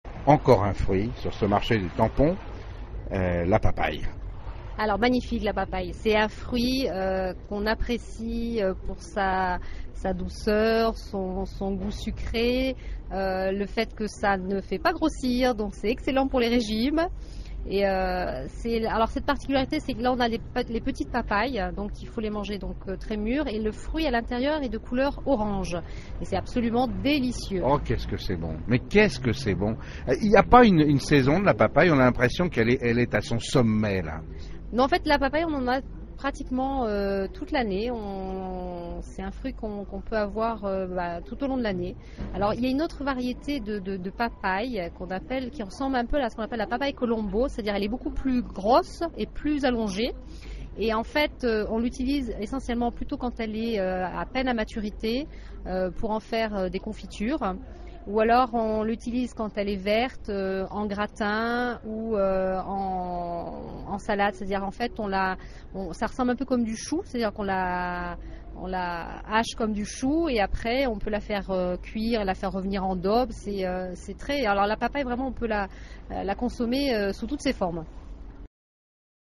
sur le Marché de Tampon à la Réunion. Reportage